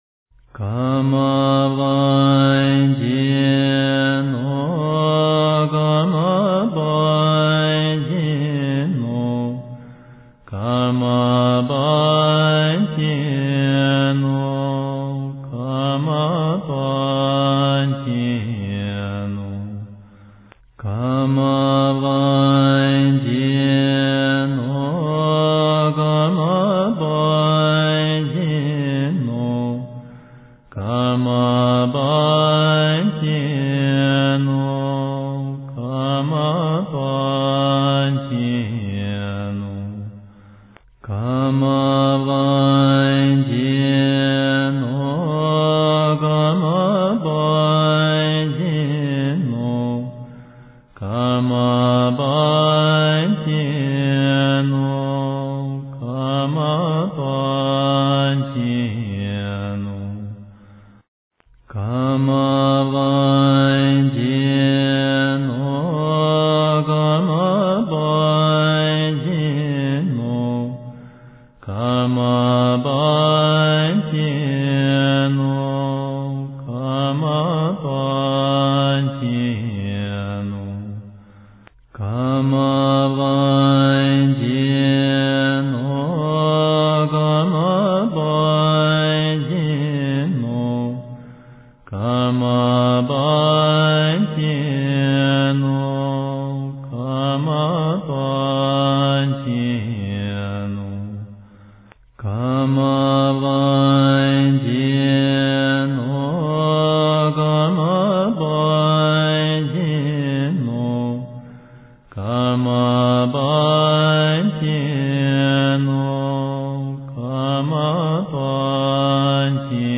标签: 佛音真言佛教音乐